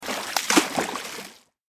دانلود آهنگ دریا 16 از افکت صوتی طبیعت و محیط
دانلود صدای دریا 16 از ساعد نیوز با لینک مستقیم و کیفیت بالا
جلوه های صوتی